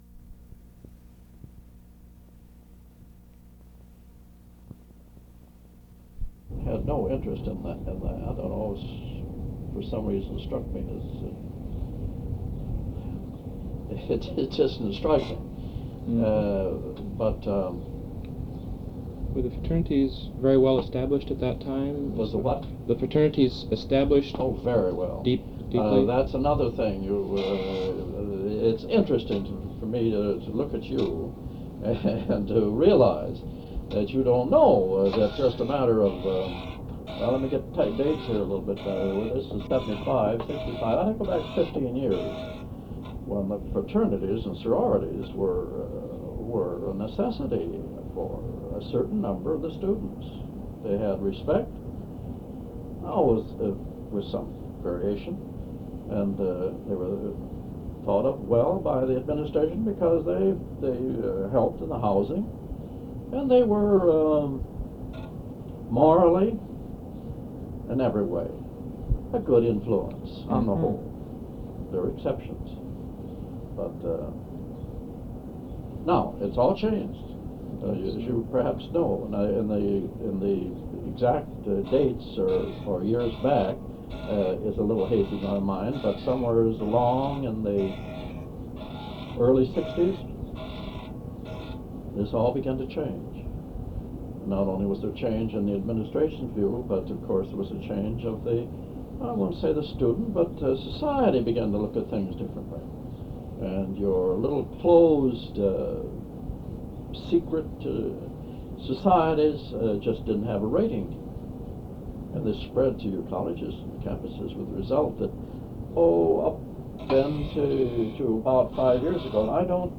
Original Format: Audio cassette tape